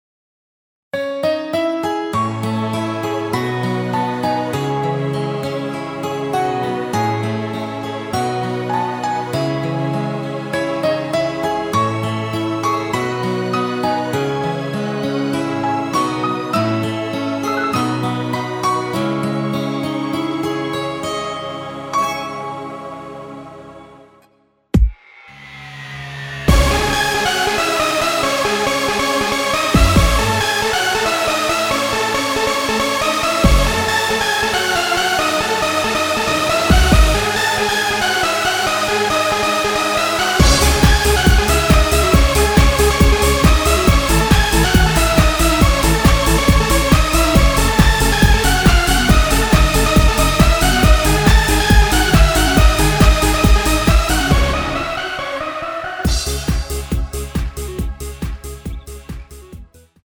원키에서(+1)올린 MR입니다.
C#m
앞부분30초, 뒷부분30초씩 편집해서 올려 드리고 있습니다.
중간에 음이 끈어지고 다시 나오는 이유는